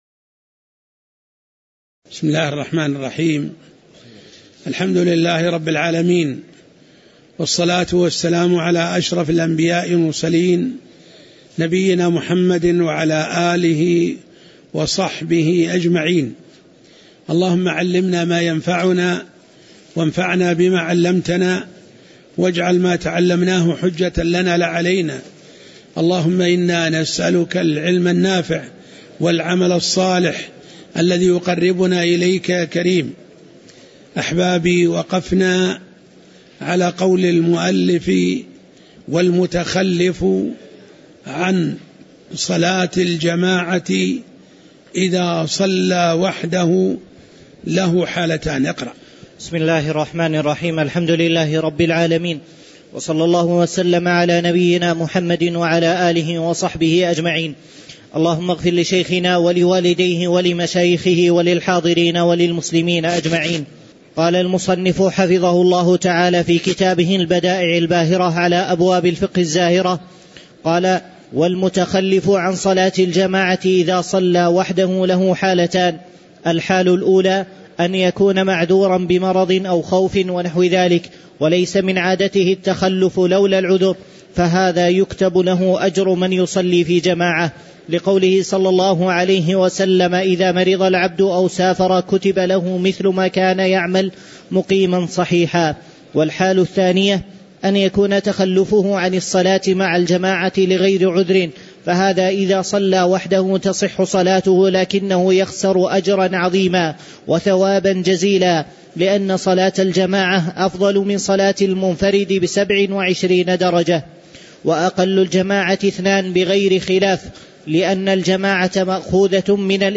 تاريخ النشر ٣٠ ربيع الثاني ١٤٣٩ هـ المكان: المسجد النبوي الشيخ